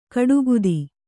♪ kaḍugudi